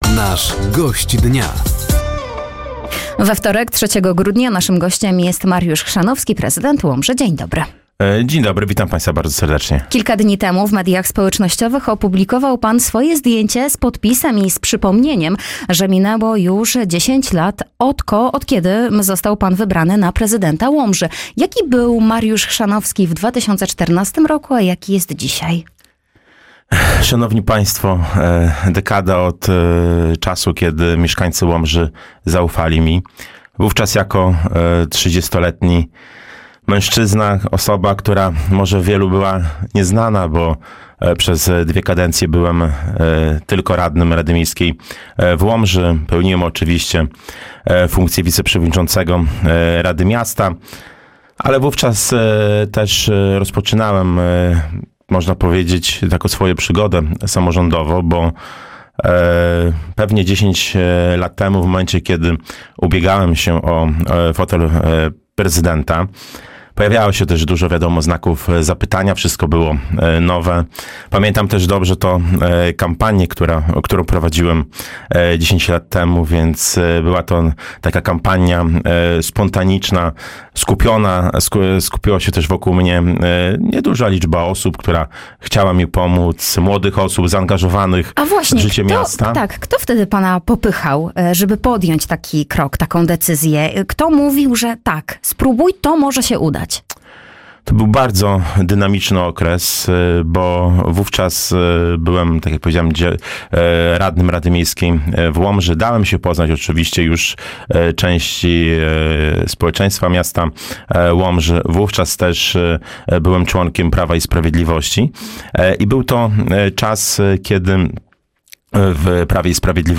Gościem Dnia Radia Nadzieja był Mariusz Chrzanowski, prezydent Łomży.